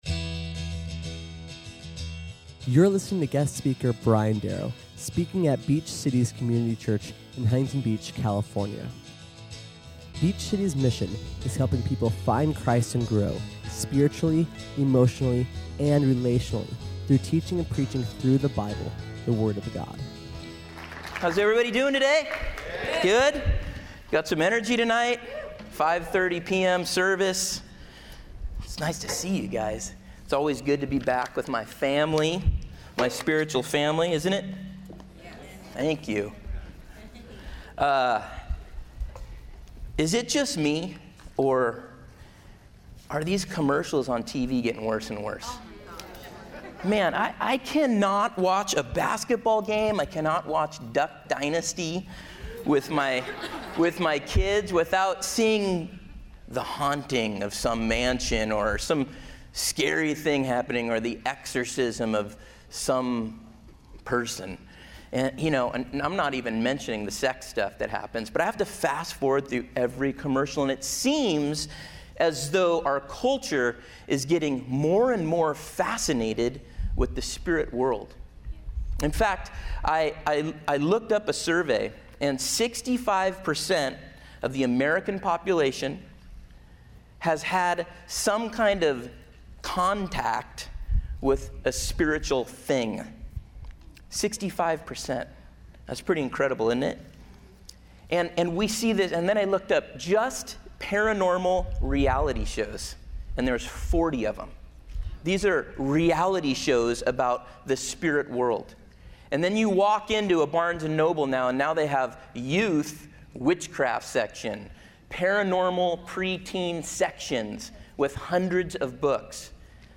SERMON AUDIO: SERMON NOTES: